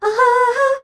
AHAAA   A.wav